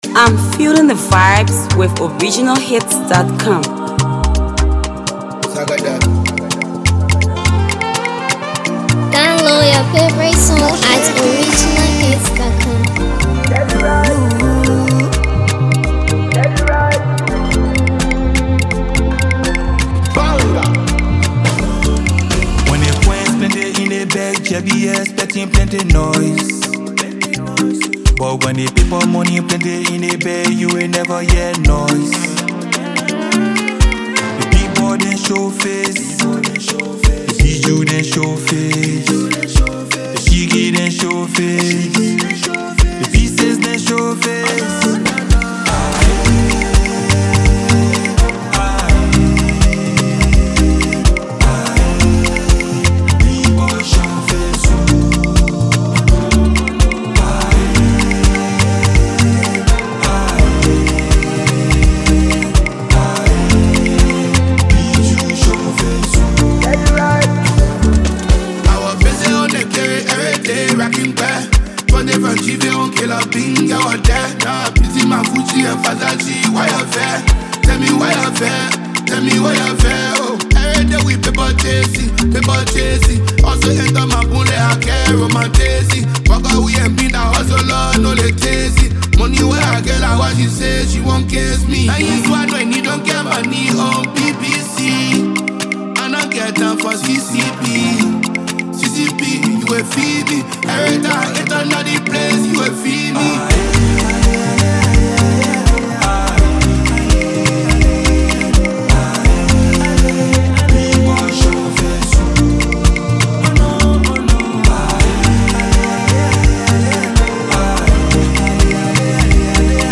Afro-pop
vibrant and powerful masterpiece